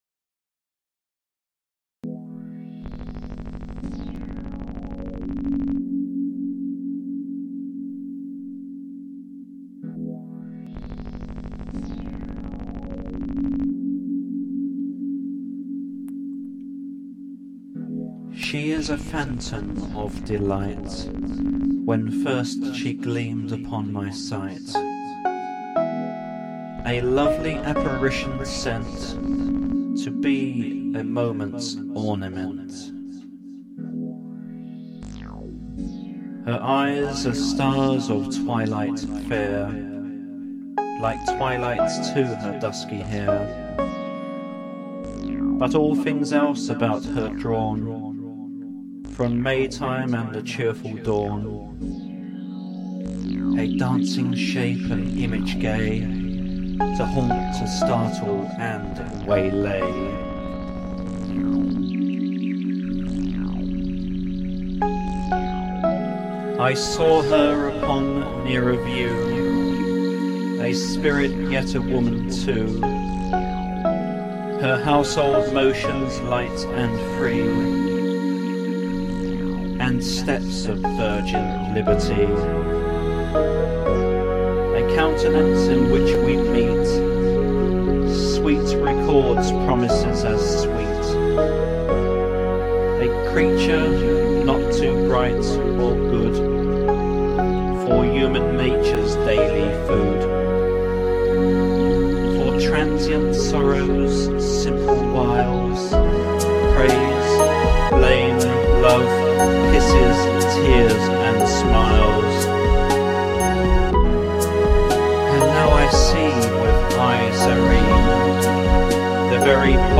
Here's Hyperbole's version of a 16th Century poem.